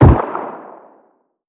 CookoffSounds / shotrocket / far_1.wav
Cookoff - Improve ammo detonation sounds